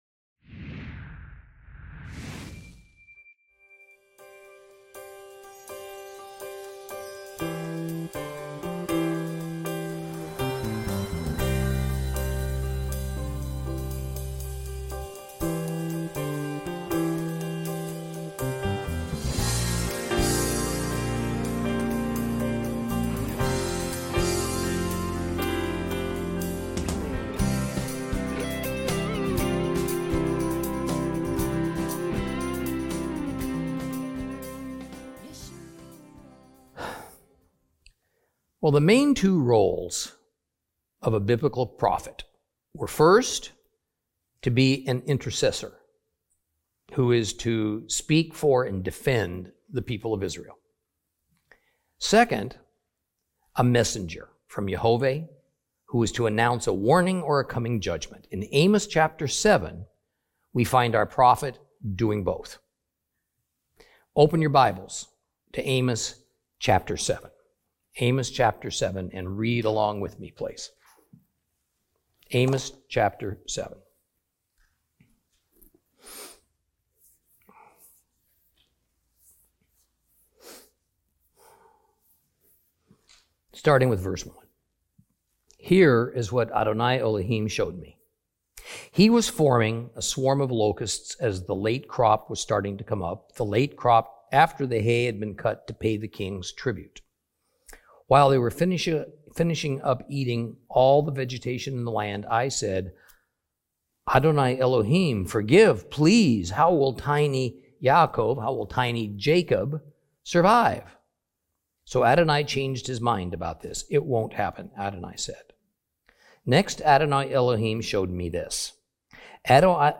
Teaching from the book of Amos, Lesson 12 Chapter 7.